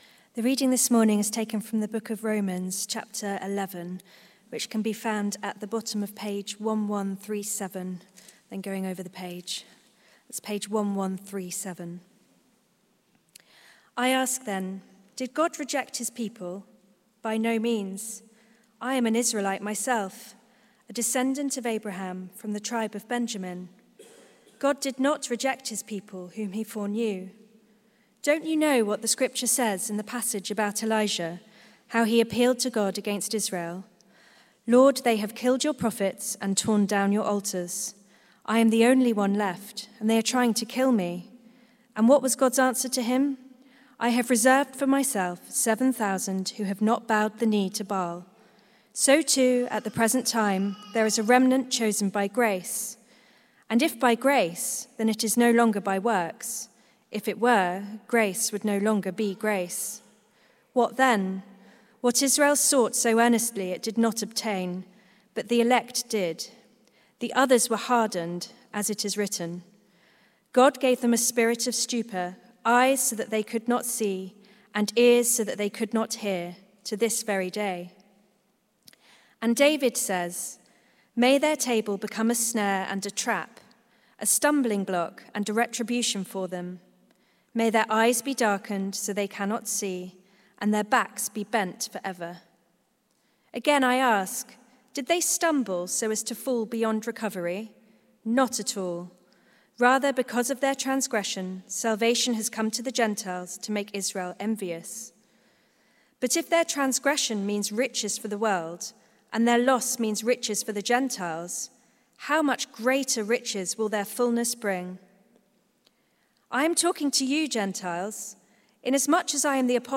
It Depends on the Kindness of God Sermon